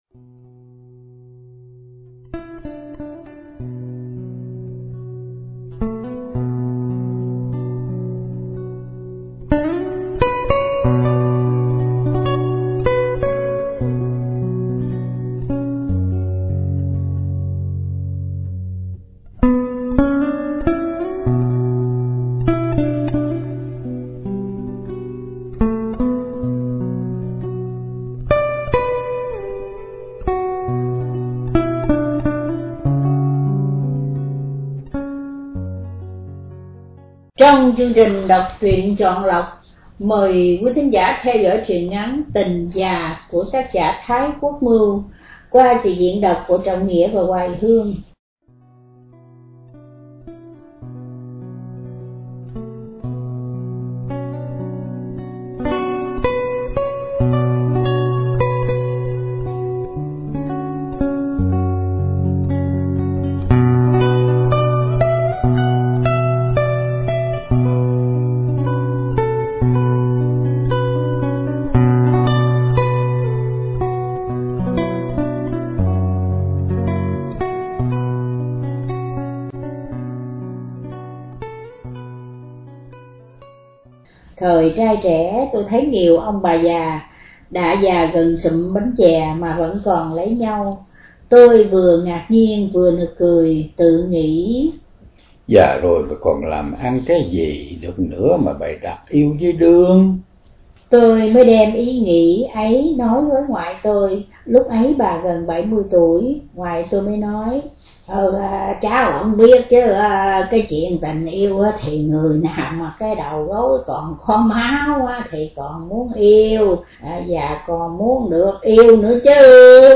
Đọc Truyện Chọn Lọc – Truyện Ngắn ” Tình Già ” – Thái Quốc Mưu – Radio Tiếng Nước Tôi San Diego